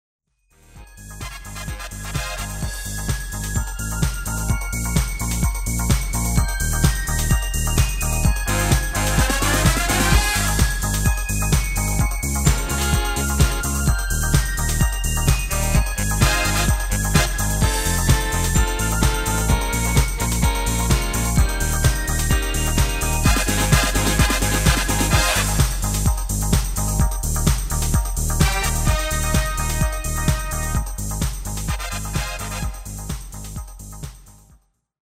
Basic MIDI File Euro 8.50
Demo's zijn eigen opnames van onze digitale arrangementen.